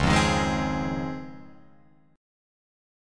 C#M9 chord